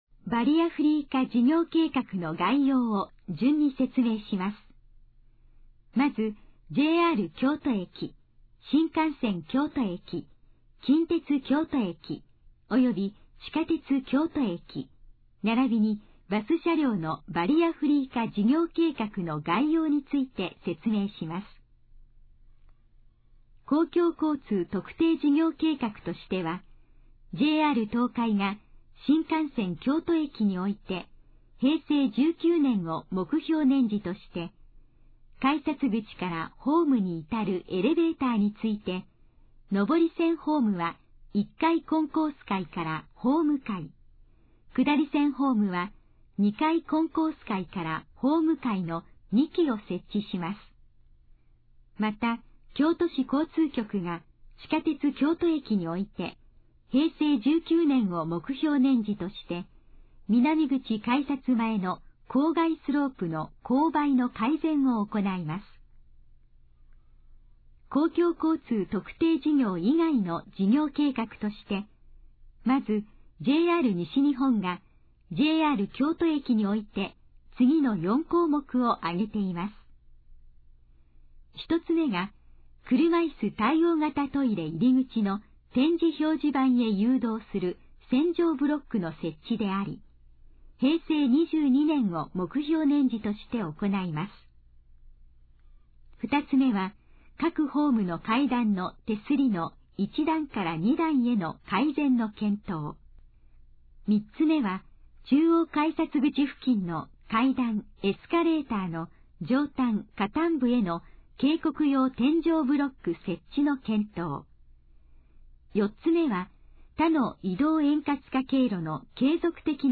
以下の項目の要約を音声で読み上げます。
ナレーション再生 約598KB